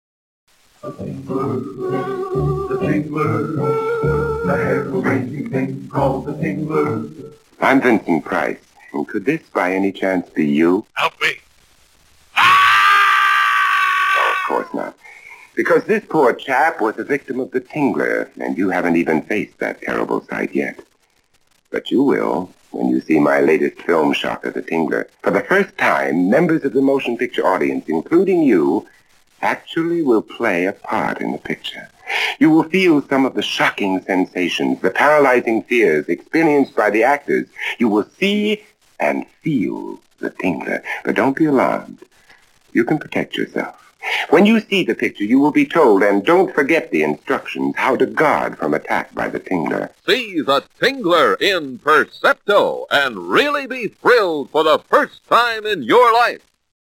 10, 20, 30, and 60 second radio spots
The-Tingler-Vincent-Price-60-converted.mp3